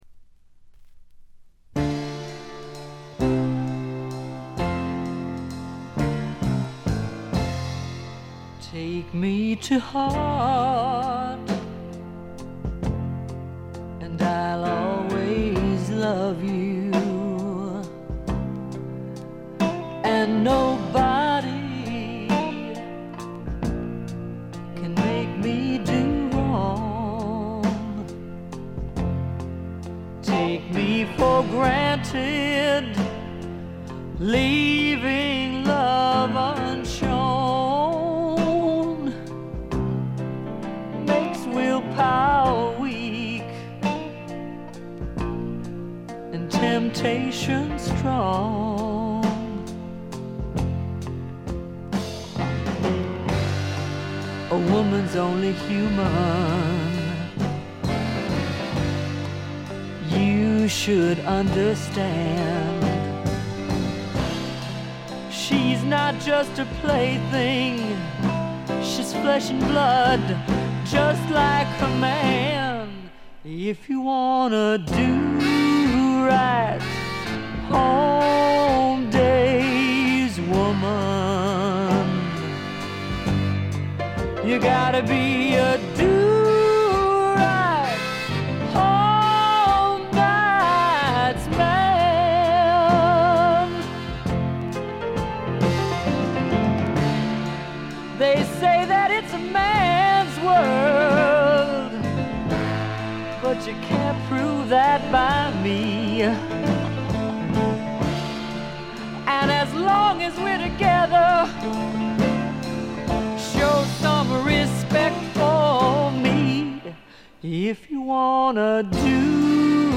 ほとんどノイズ感無し。
試聴曲は現品からの取り込み音源です。
Recorded At - Muscle Shoals Sound Studios